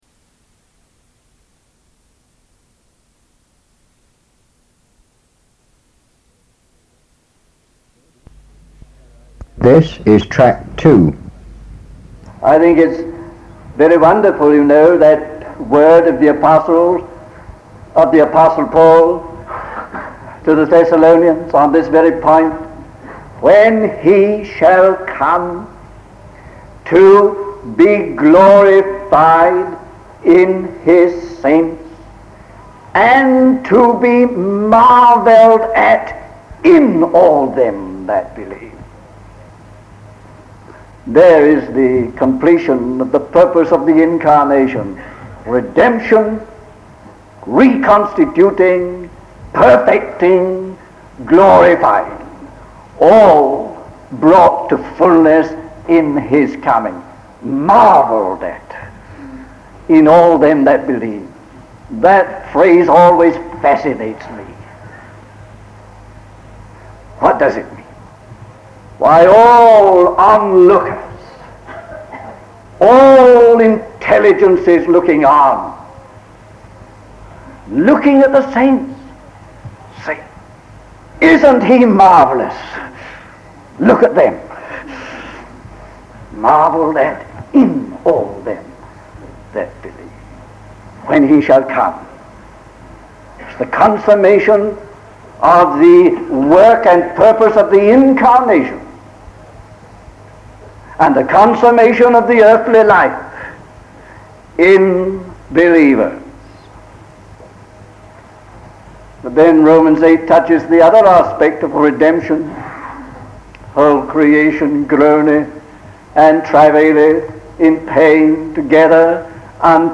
In this sermon, T. Austin-Sparks explores the multifaceted nature of redemption as fulfilled in the coming of the Son of Man. He highlights the completion of the incarnation's purpose in glorifying believers, redeeming creation, and defeating Satan's kingdom. Emphasizing the cosmic scope of Christ's victory and the church's role, Sparks calls believers to stand firm in hope and anticipation of the Lord's return.